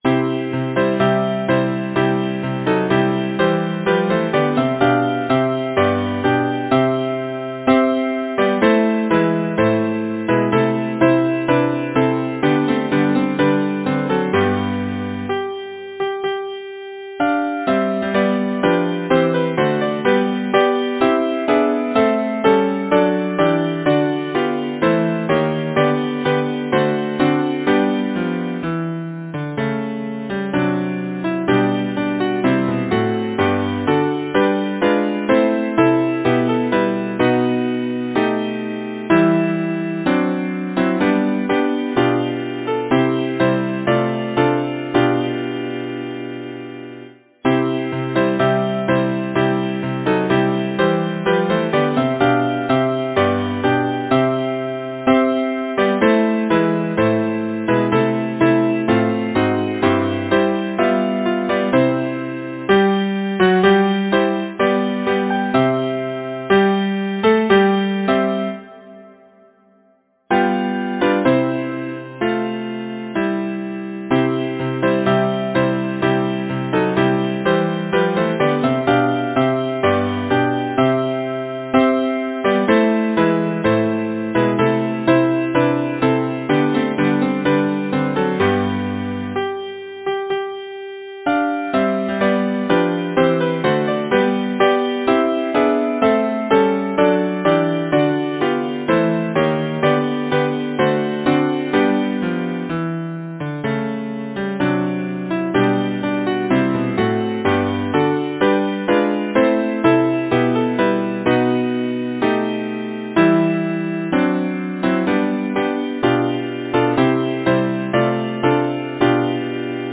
Title: Monarch winter Composer: Stephen Samuel Stratton Lyricist: William Smallcombe Passmore Number of voices: 4vv Voicing: SATB Genre: Secular, Partsong
Language: English Instruments: A cappella